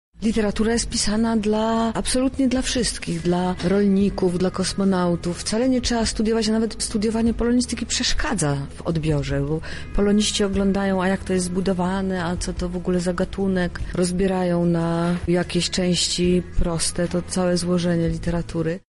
Olga Tokarczuk w 2018 roku gościła w Lublinie przy okazji „Spotkań z Mistrzami” w Centrum Spotkania Kultur. Wtedy też podzieliła się ze słuchaczami Radia Centrum swoimi przemyśleniami na temat literatury: